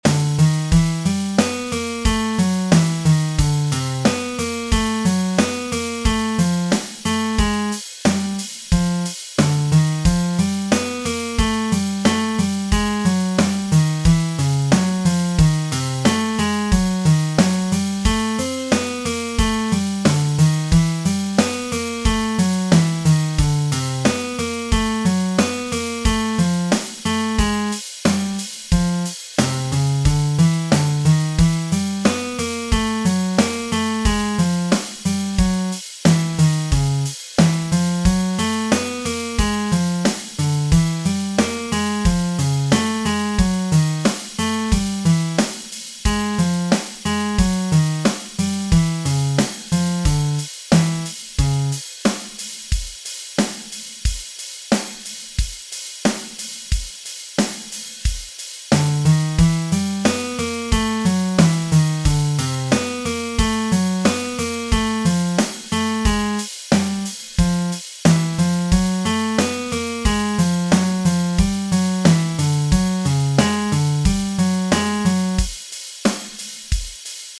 A short, mysterious but lighthearted song made in LMMS.